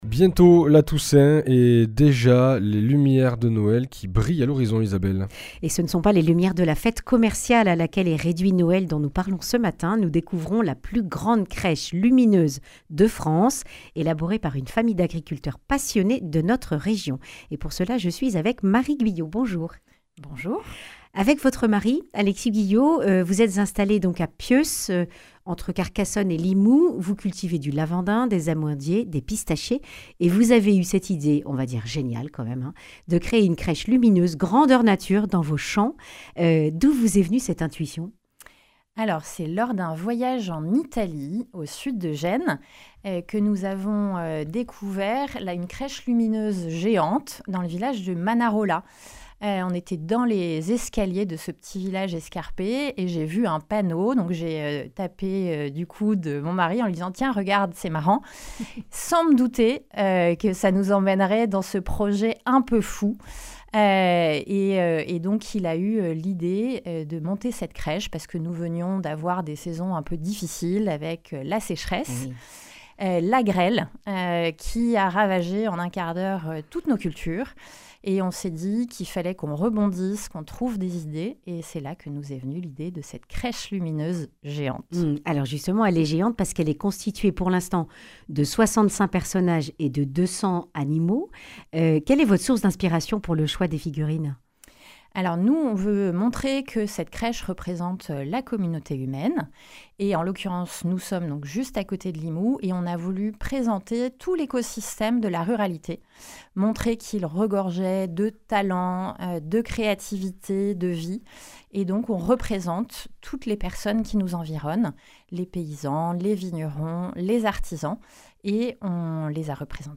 Accueil \ Emissions \ Information \ Régionale \ Le grand entretien \ La plus grande crèche de France est dans notre région et elle a besoin de vous !